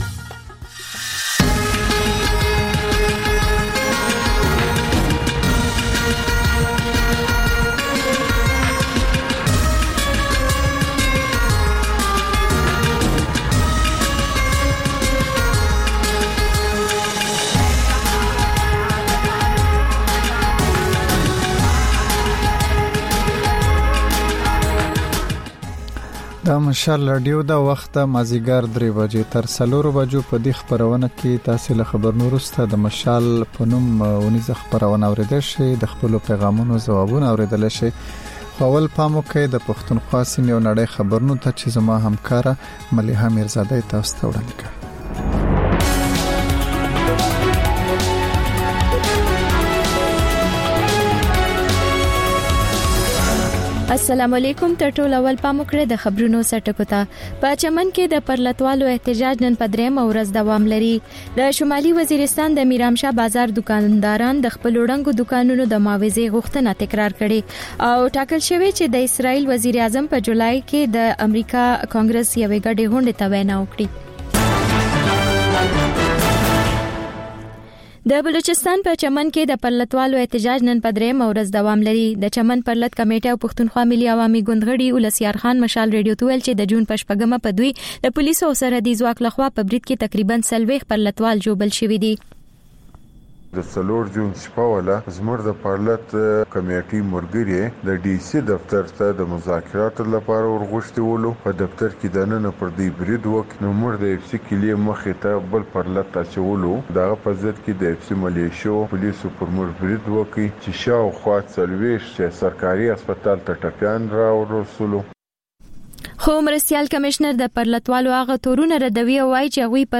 د مشال راډیو درېیمه یو ساعته ماسپښینۍ خپرونه. تر خبرونو وروسته، رپورټونه او شننې خپرېږي.